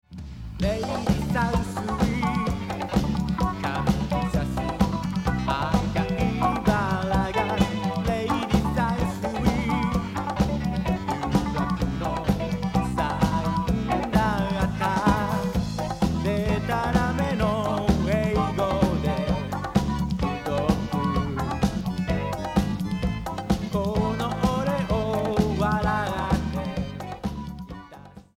Vocal
Guiter
Bass
Keyboad
Chorus & Synthesizer programing
Drums & RX-11 RX-21L programer